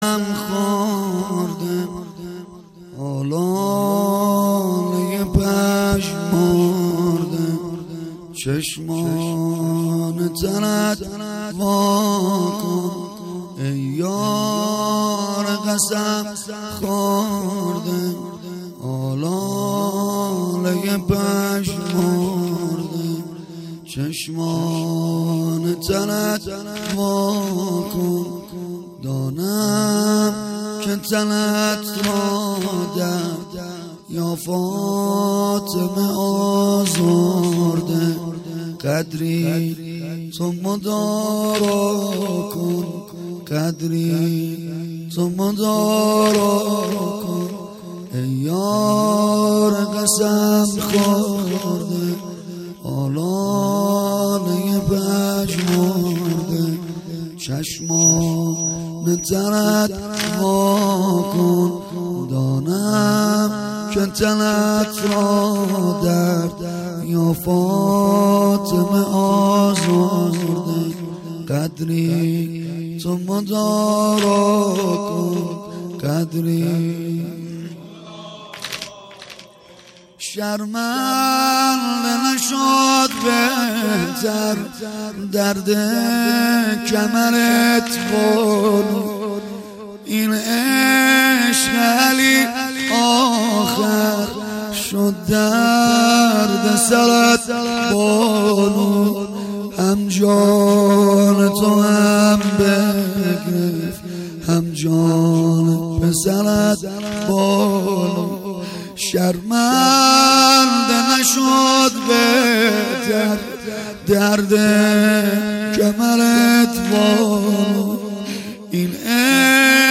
قسمت دوم -مداحی.mp3
قسمت-دوم-مداحی.mp3